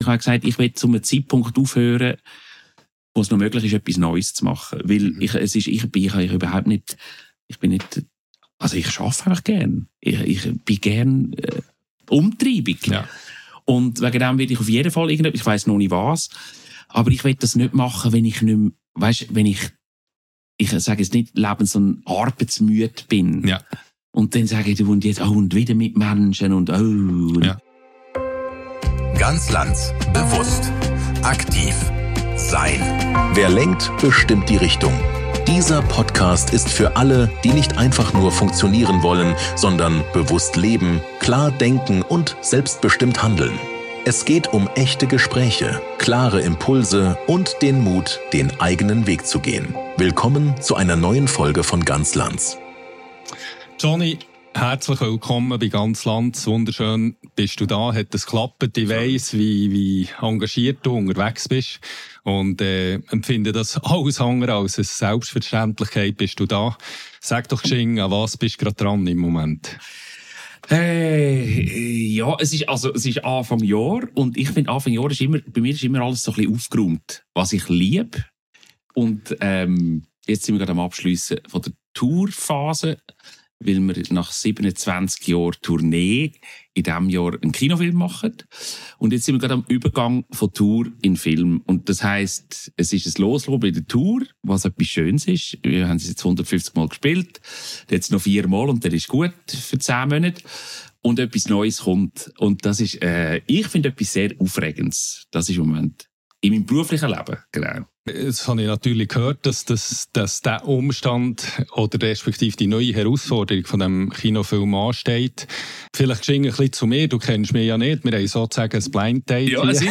In diesem Gespräch spricht Jonny offen über Erfolg, Zweifel, Leistungsdruck und die Kraft des Humors im echten Leben. Es geht um Verantwortung, Verletzlichkeit und den Mut, sich selbst treu zu bleiben – auch hinter der Bühne. Ein ehrliches, inspirierendes Gespräch über Sichtbarkeit, Selbstführung und echte Menschlichkeit.